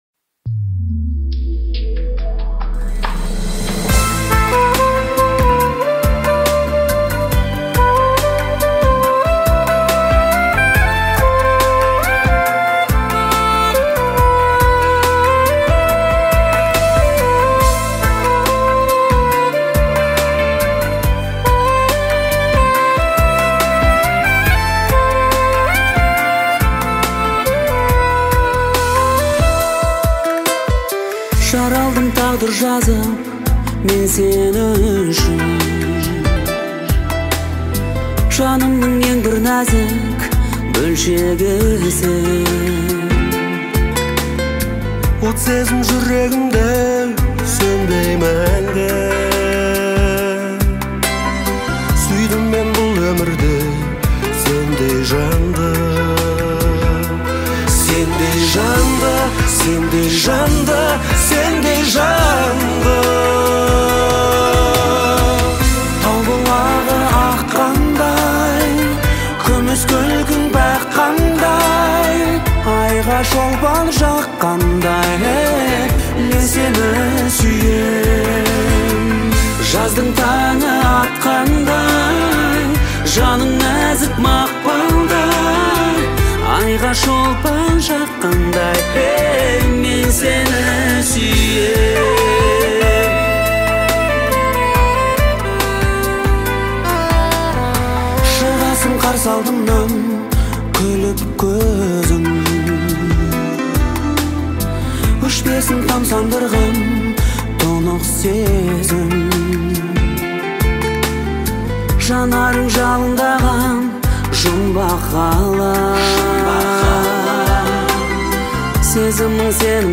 романтическая баллада
гармоничные мелодии и чувственные вокалы